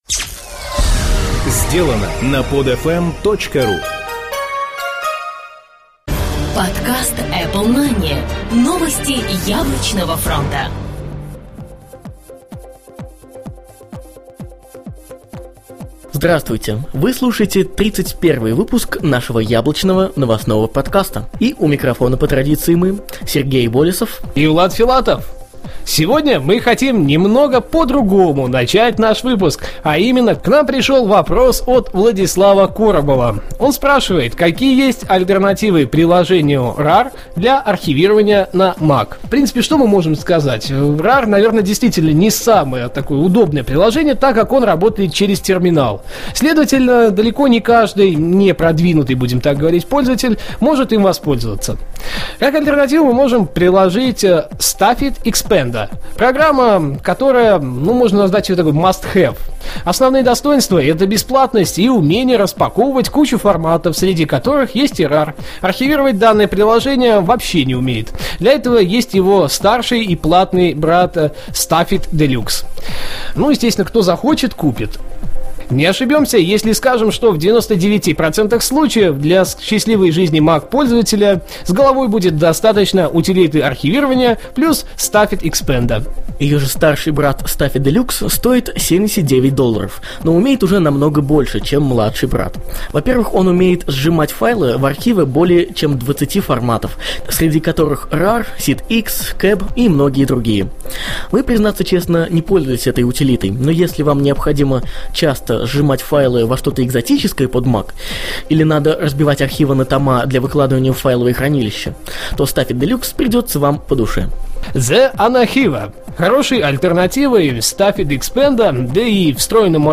"Apple Mania" - еженедельный новостной Apple подкаст
Жанр: новостной Apple-podcast